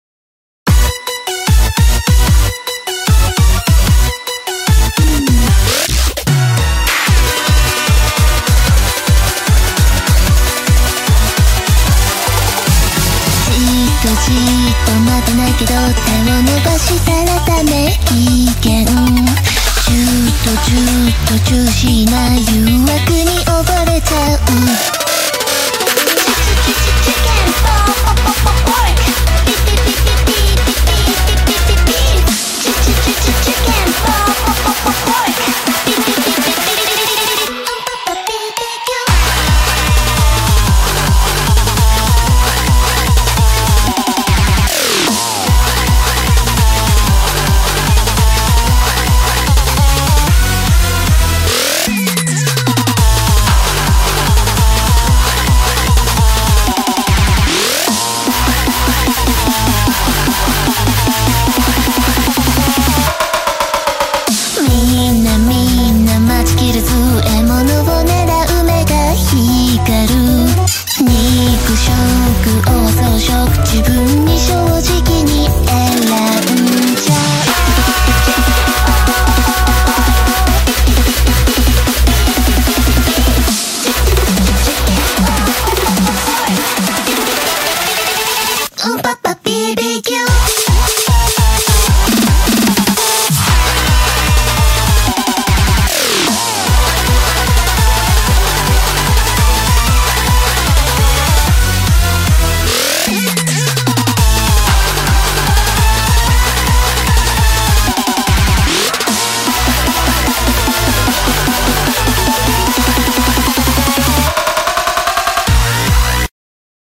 BPM150-300
Audio QualityPerfect (Low Quality)